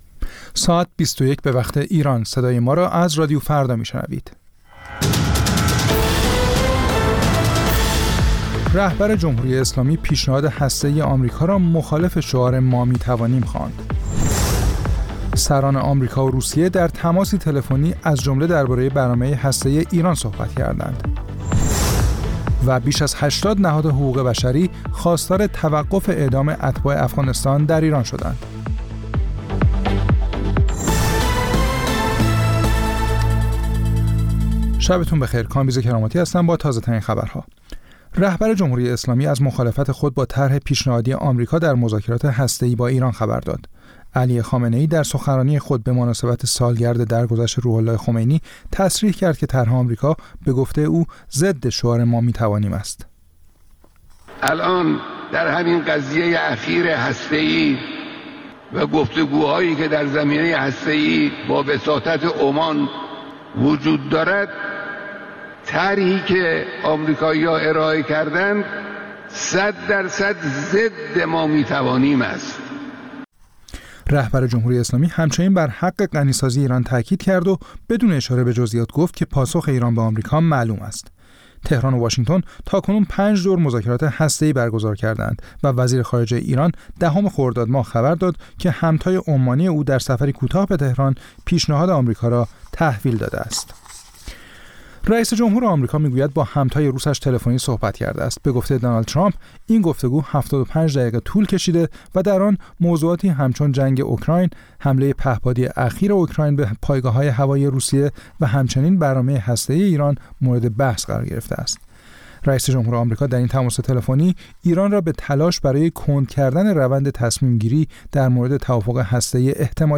سرخط خبرها ۲۱:۰۰
پخش زنده - پخش رادیویی